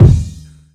Kick34.wav